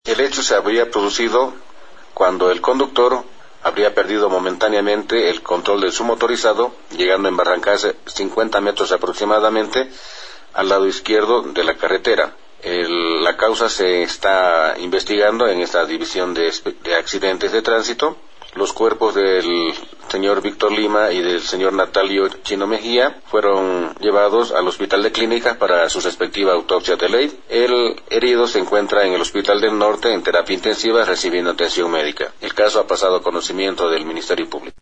Pablo García, Director de Transito de El Alto